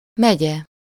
Ääntäminen
Synonyymit local administrative unit Ääntäminen US UK : IPA : /ˈkaʊnti/ Lyhenteet ja supistumat (laki) Cnty.